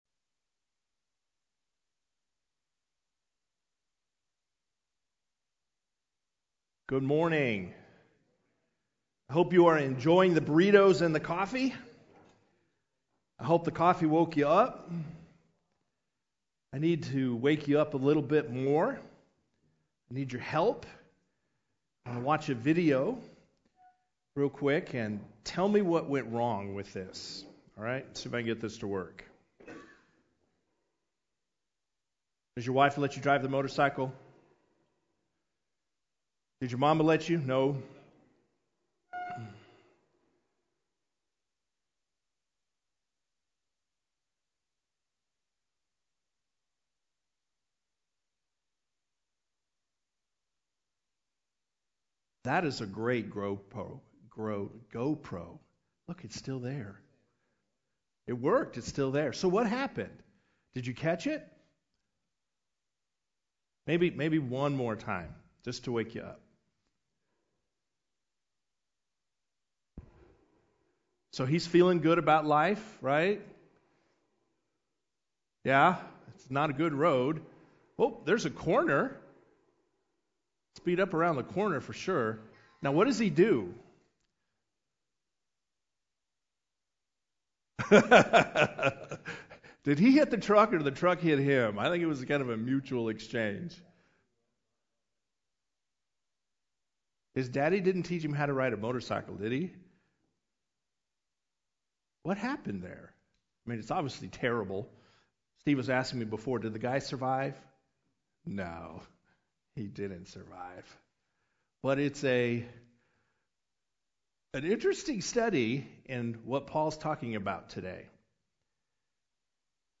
Men’s Breakfast Bible Study 8/11/20